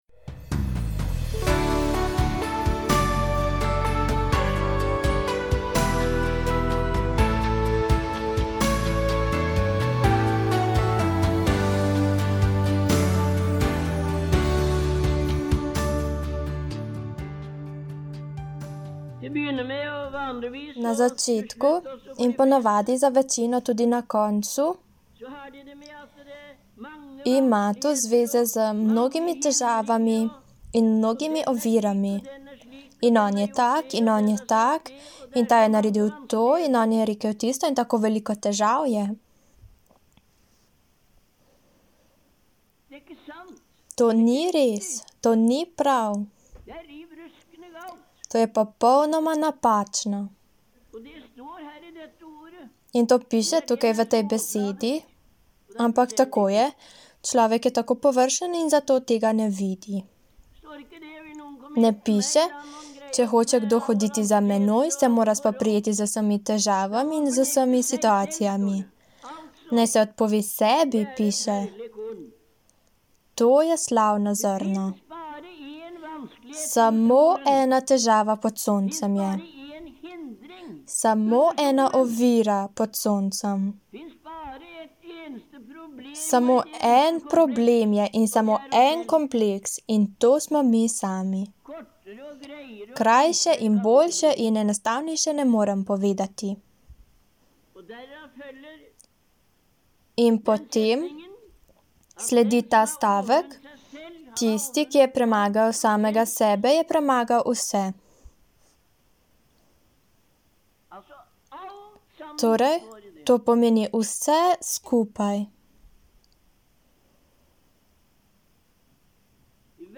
Taleutdrag fra januar 1973